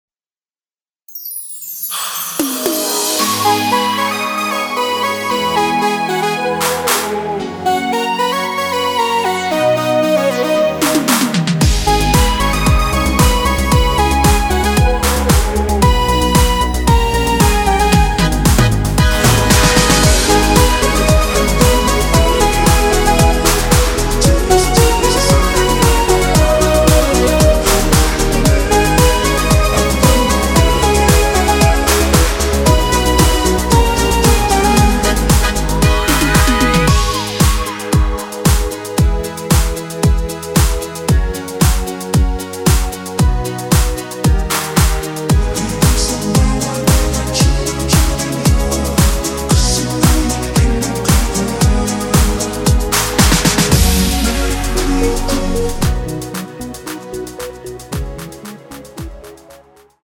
원키에서(-6)내린 코러스 포함된 MR입니다.(미리듣기 확인)
G#m
앞부분30초, 뒷부분30초씩 편집해서 올려 드리고 있습니다.
중간에 음이 끈어지고 다시 나오는 이유는